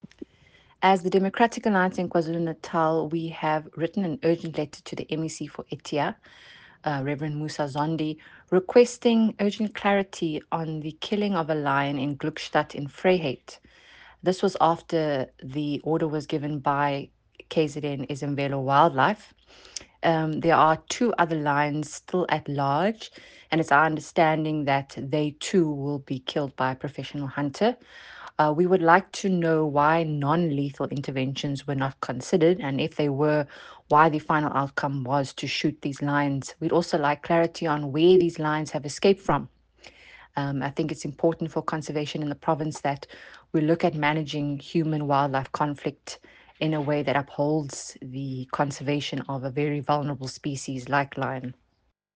Issued by Hannah Lidgett, MPL – DA KZN Spokesperson on EDTEA
Note to Editors: Please find Hannah Lidgett sound bite in